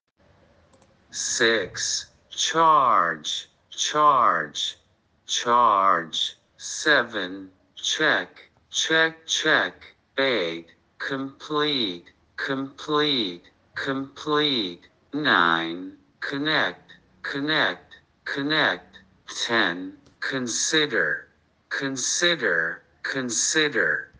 Lesson 2 : 6-10 PhoneticsCopyCopy